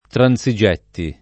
transigere [tranS&Jere] v.; transigo [tranS&go], ‑gi — pass. rem. transigetti [